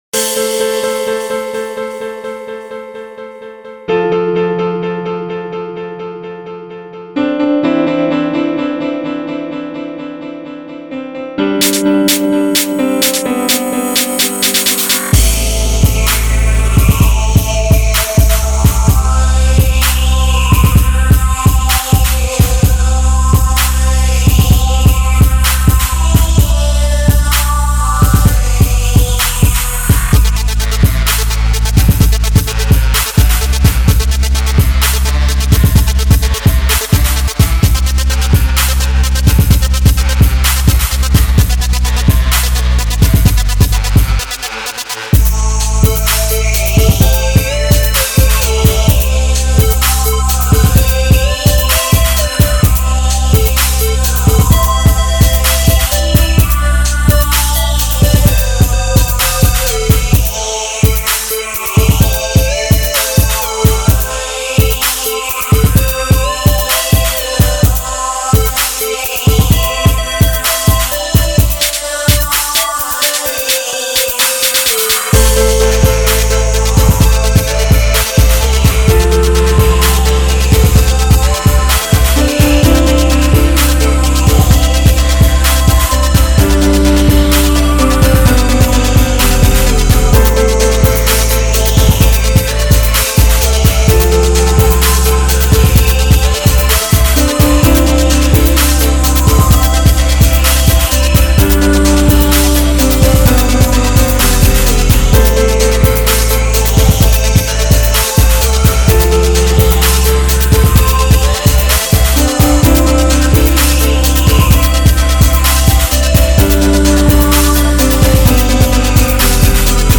A beat I made on fruity loops 9.
I developed the beat from the piano sound.
rap81.mp3